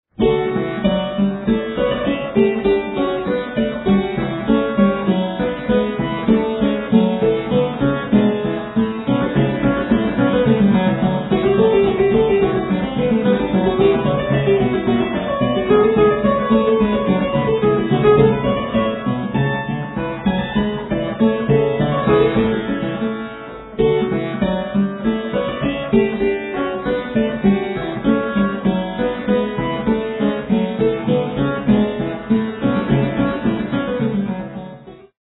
Prelude for keyboard in D major